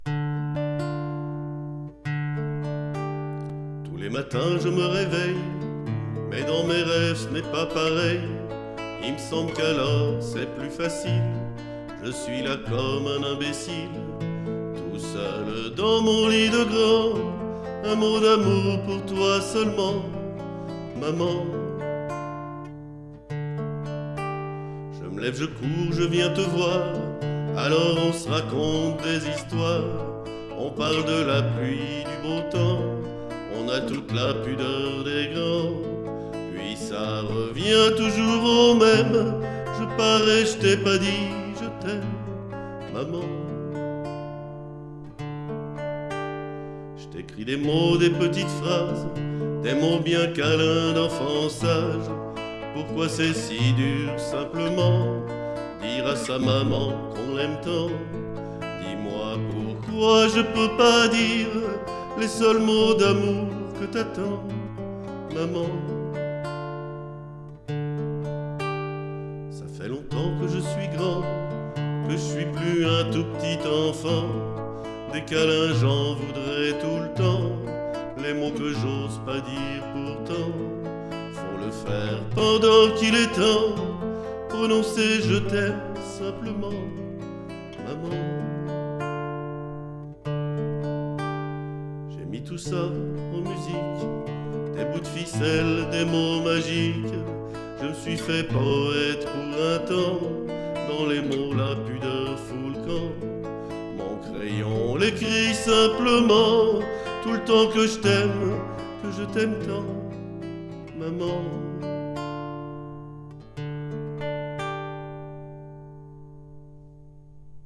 Texte et chant